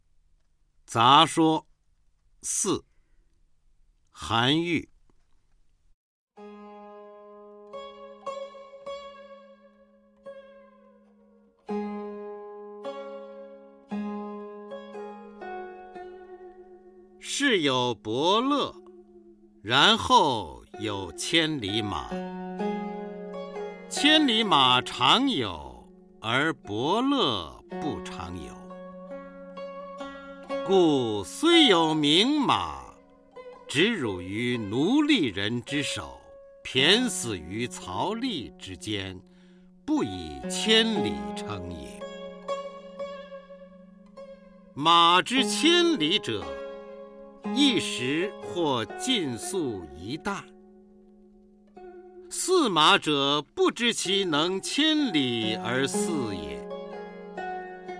初中生必背古诗文标准朗诵（修订版）（1）-09-方明-杂说（四） 唐 韩愈